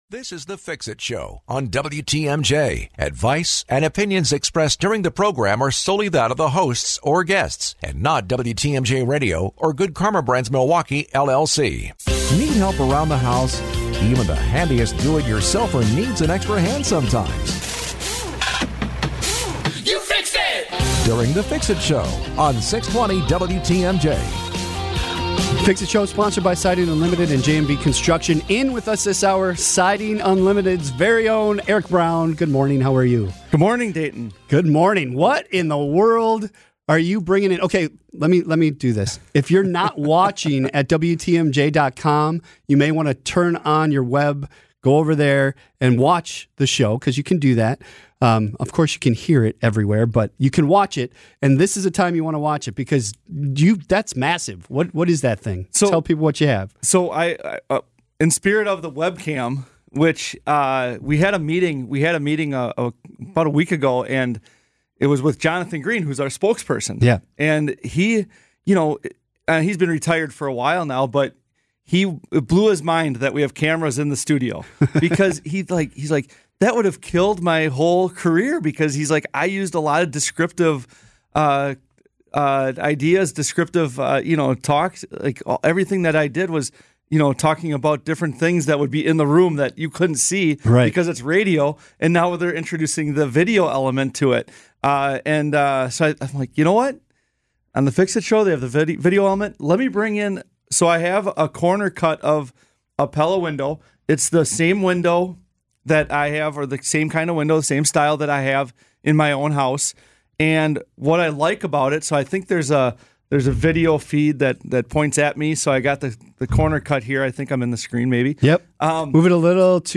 discuss home improvement projects and talk with special guests every Saturday morning.